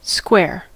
square: Wikimedia Commons US English Pronunciations
En-us-square.WAV